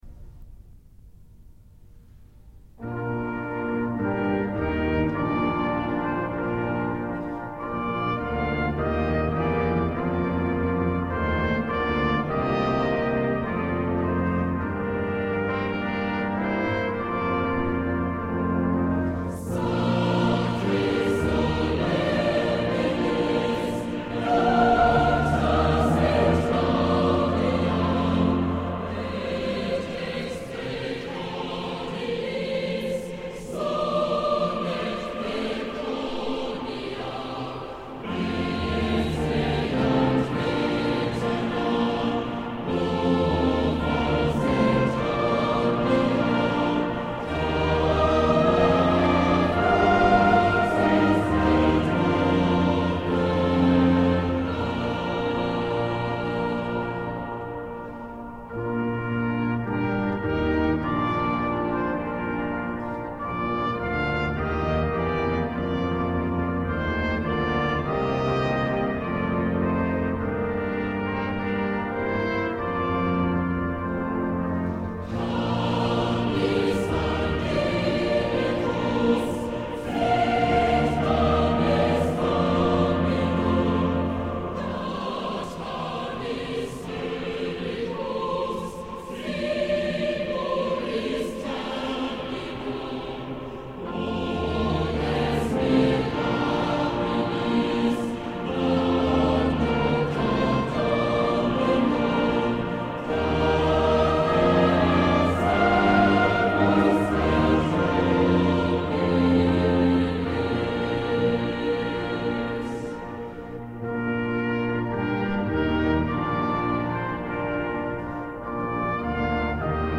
coro.mp3